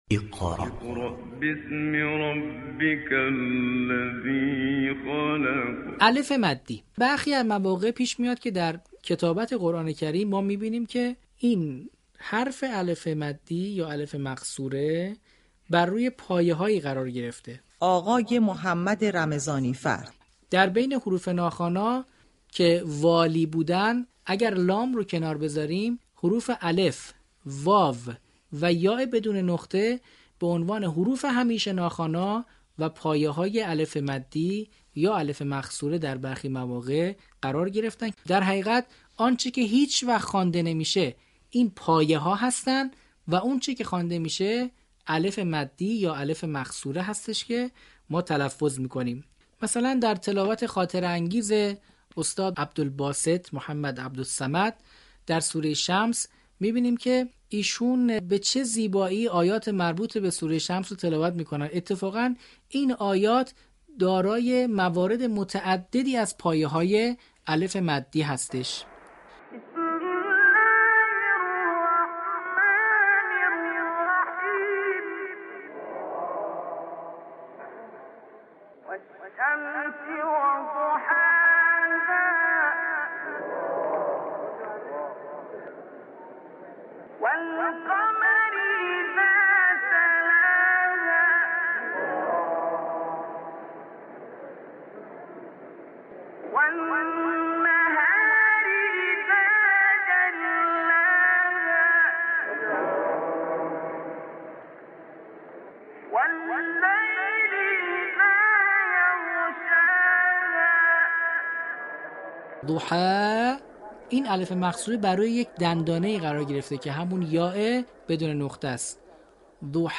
در ادامه سلسله برنامه های آموزش روخوانی و روان‌خوانی قرآن كریم در رادیو قرآن ، كارشناس برنامه با تشریح ویژگی‌های «الف مقصوره» و پایه‌های ناخوانا، نكات كاربردی این مبحث را با ذكر نمونه‌هایی از تلاوت استاد عبدالباسط ارائه كرد.